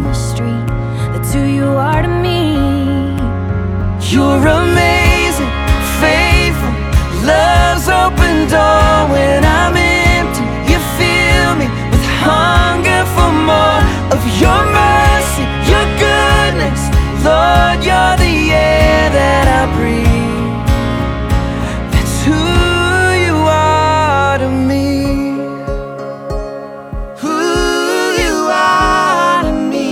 • Christian & Gospel